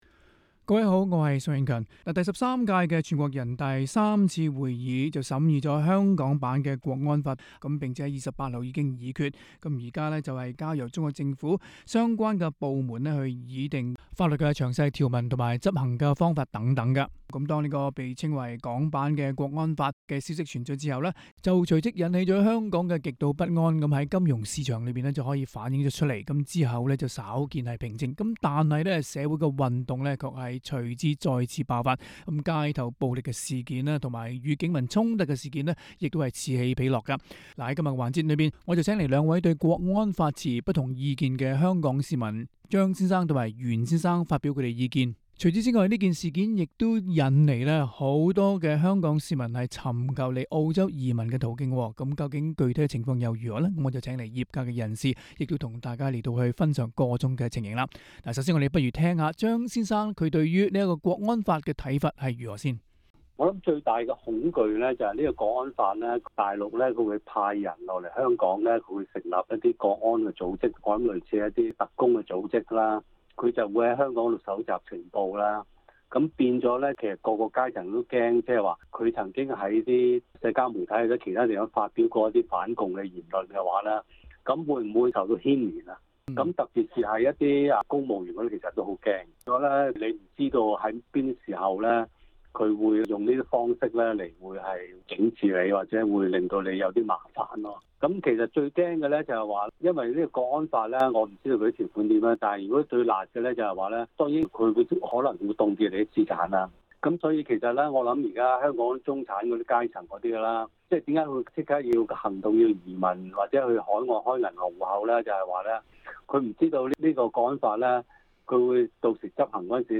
Source: LightRocket SBS廣東話節目 View Podcast Series Follow and Subscribe Apple Podcasts YouTube Spotify Download (26.62MB) Download the SBS Audio app Available on iOS and Android 人大為香港制定港區國安法， 有市民感到不安，亦有市民處之泰然。